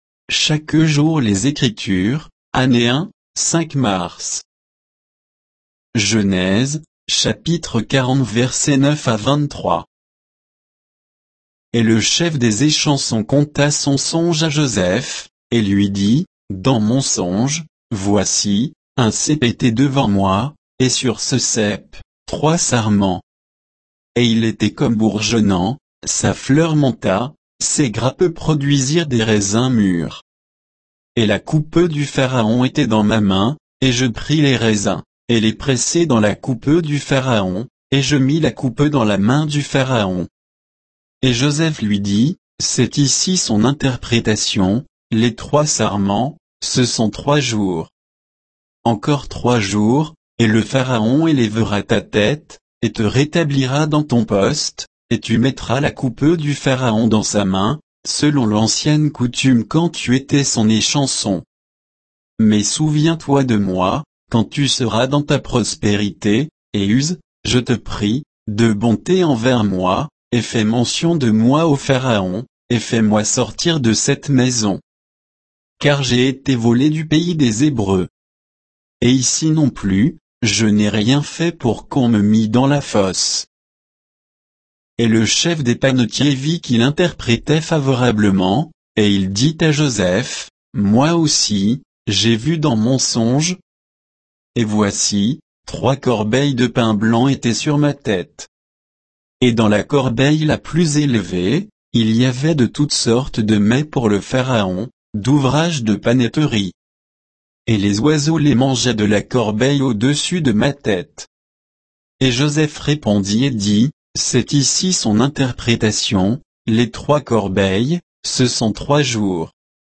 Méditation quoditienne de Chaque jour les Écritures sur Genèse 40